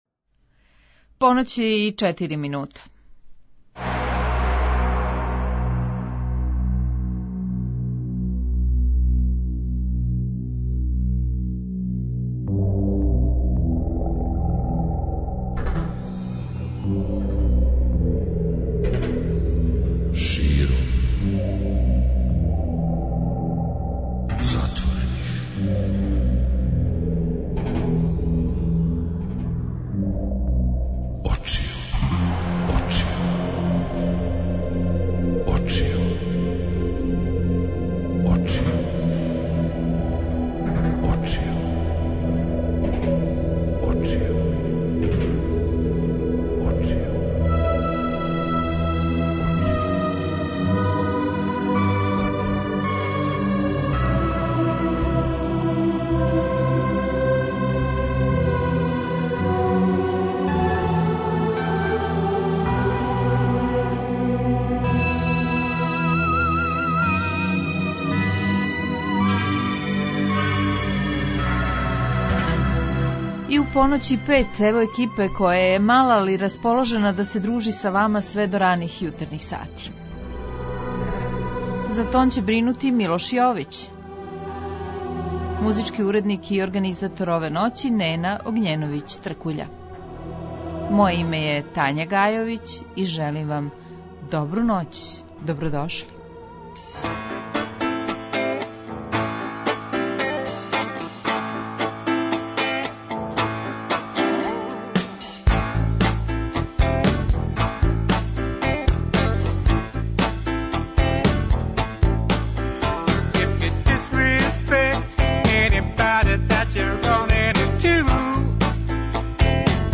Гости: бенд Лифт